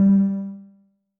effect / chiptune